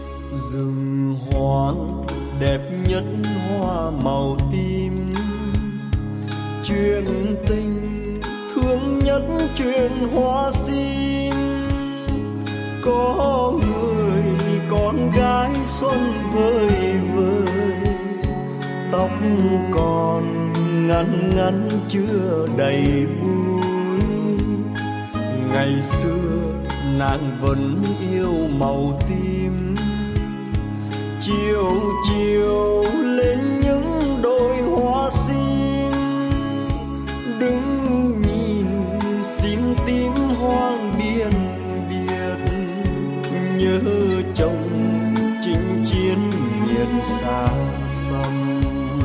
Bolero Trữ Tình